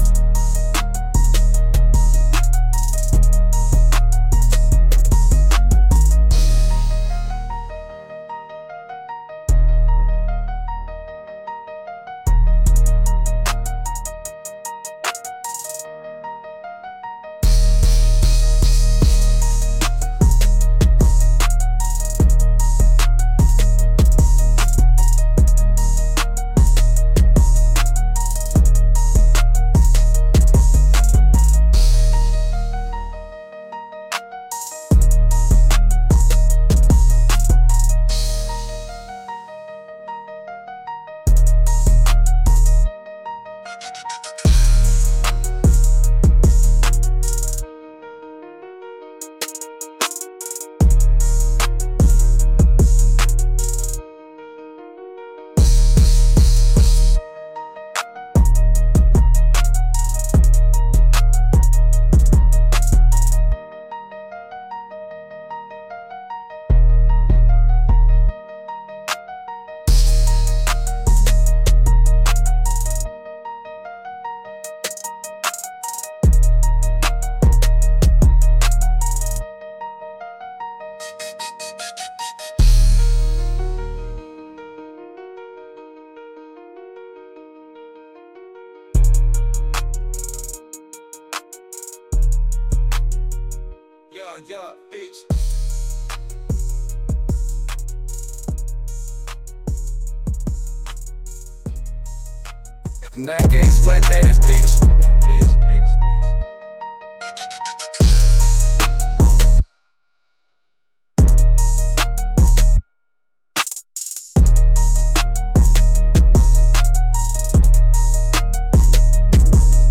aggressive